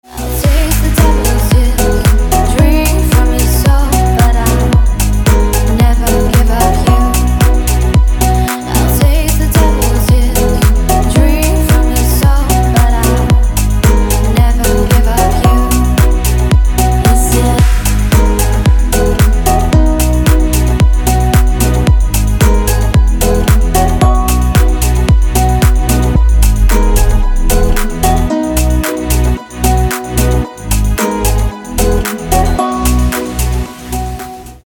• Качество: 224, Stereo
женский вокал
deep house
dance
club